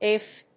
アイ‐ビーエフ